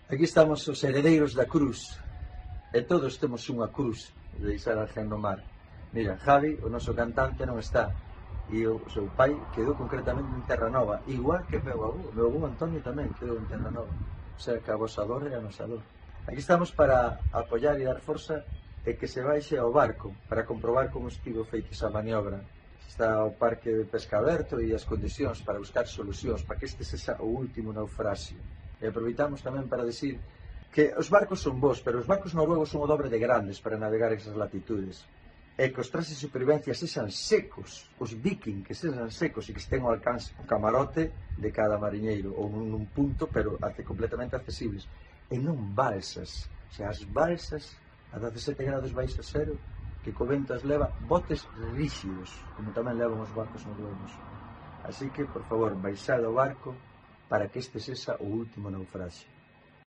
Mensaje de apoyo de Heredeiros da Crus a las familias del barco hundido en Terranova 'Villa de Pitanxo'